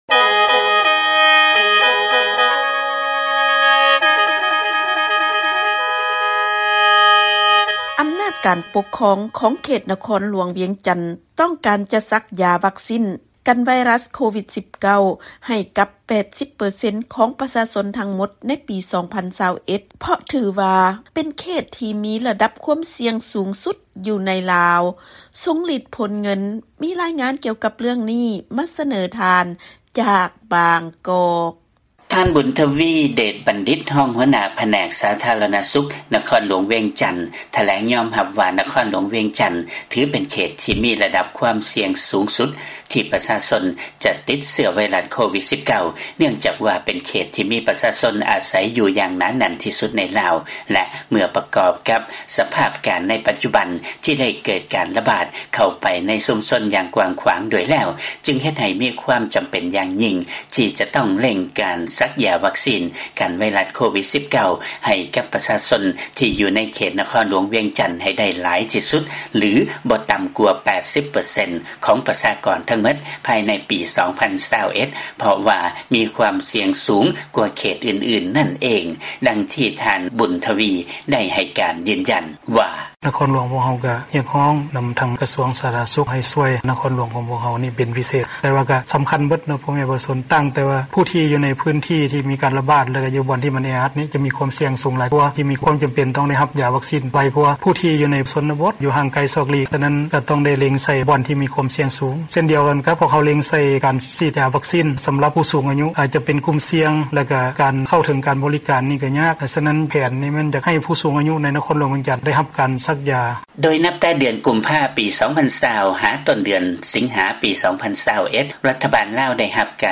ຟັງລາຍງານ ອຳນາດການປົກຄອງເຂດ ນະຄອນຫຼວງວຽງຈັນ ຕ້ອງການສັກຢາວັກຊີນກັນ COVID-19 ໃຫ້ປະຊາກອນທັງໝົດ 80 ເປີເຊັນໃນປີ 2021